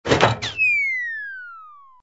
SA_canned_tossup_only.ogg